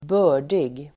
Uttal: [²b'ö:r_dig]